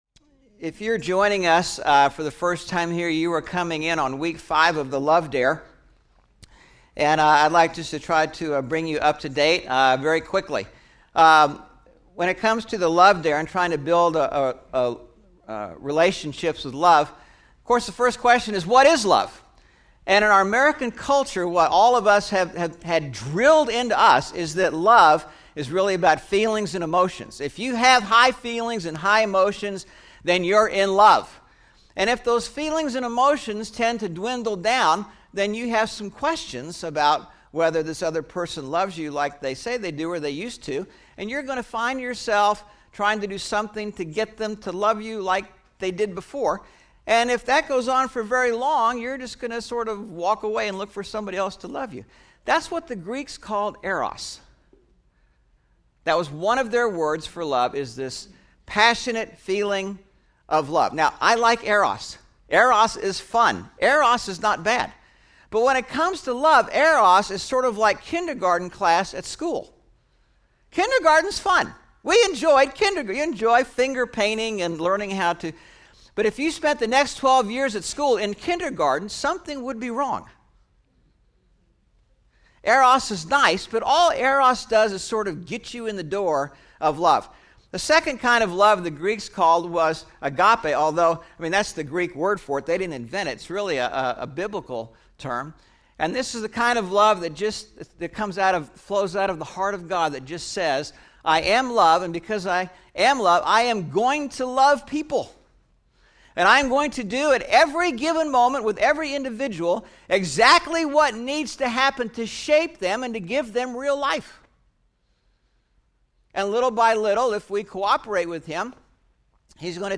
5/29/11 Sermon (The Love Dare part 5) – Churches in Irvine, CA – Pacific Church of Irvine